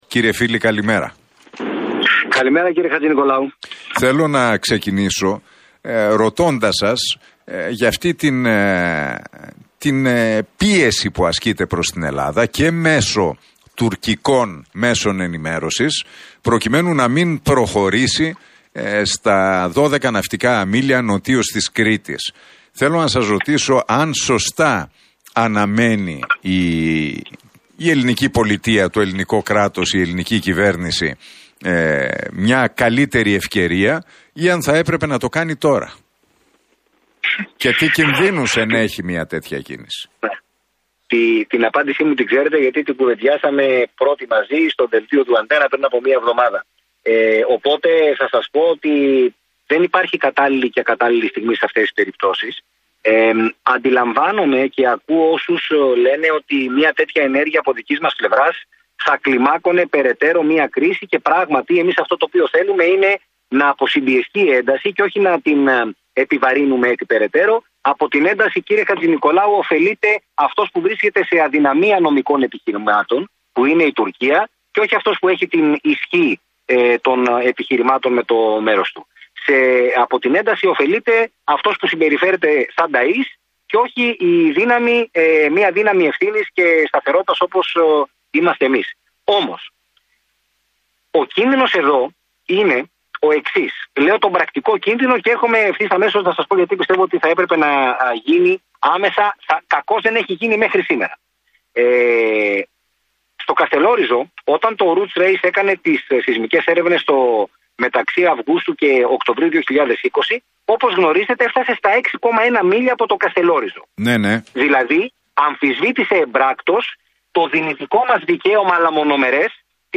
μιλώντας στον Realfm 97,8 και στην εκπομπή του Νίκου Χατζηνικολάου.